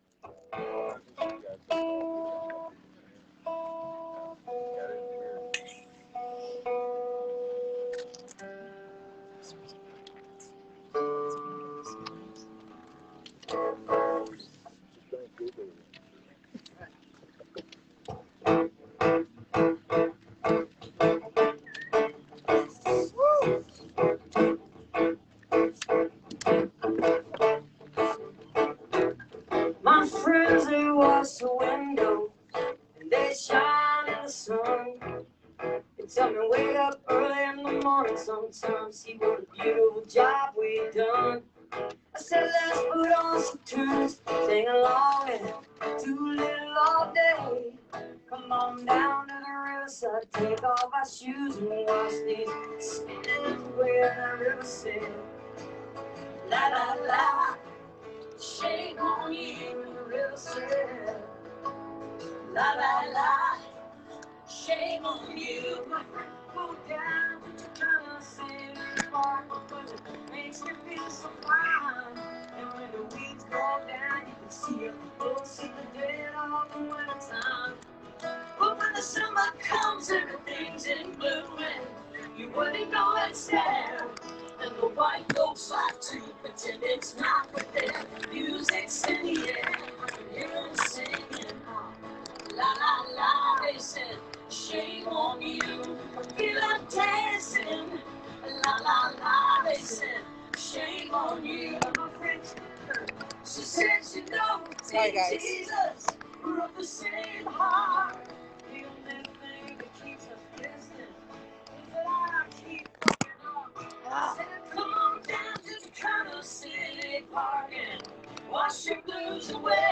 (captured from a facebook livestream)